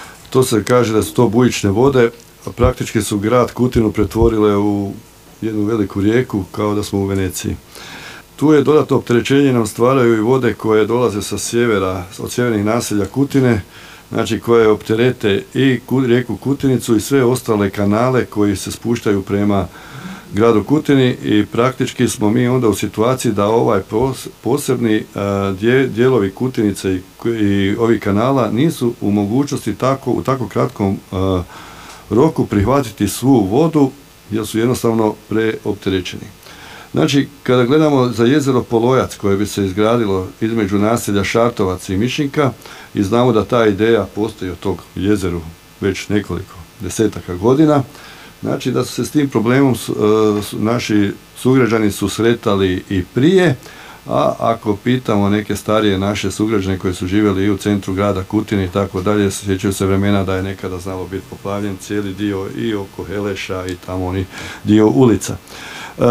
Ova nepogoda bila je i jedna od tema na Aktualnom satu 18. sjednice Gradskog vijeća Grada Kutine.
Gradonačelnik Zlatko Babić